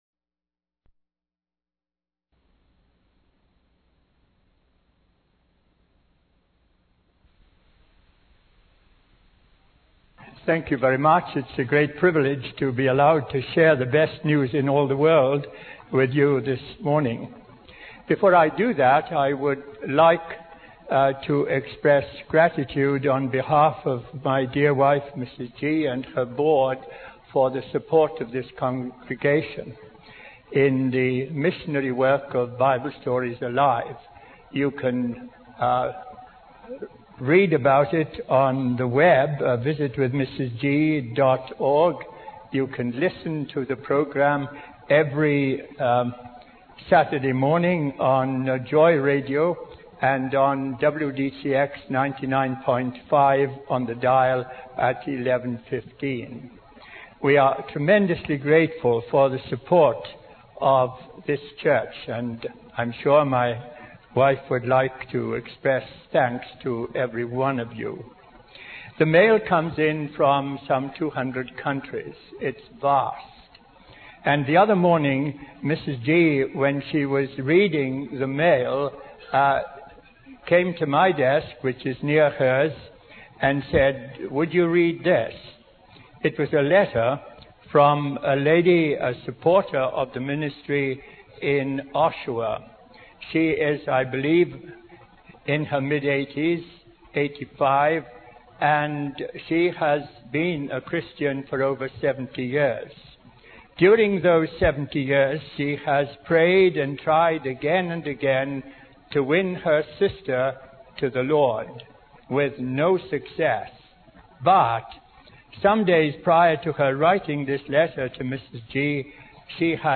In this sermon, the preacher discusses a parable told by Jesus about a prosperous farmer who had a bumper harvest.